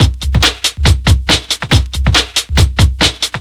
ELECTRO 01-L.wav